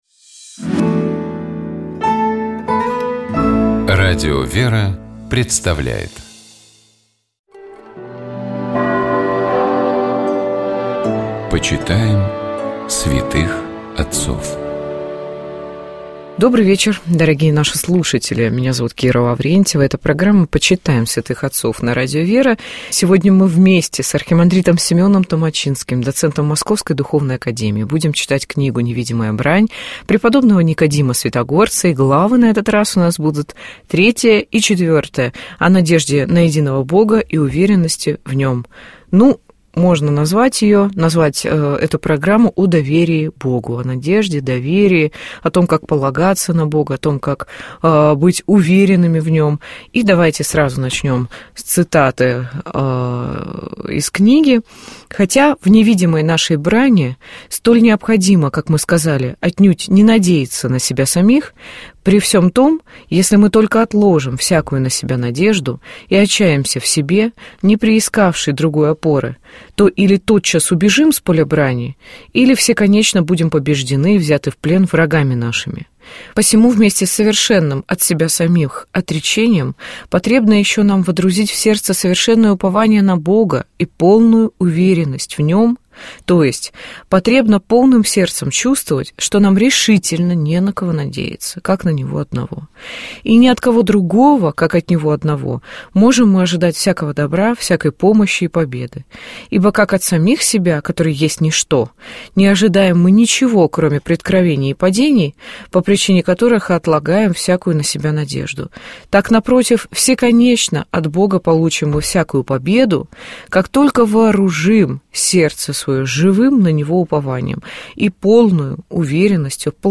Ведущая программы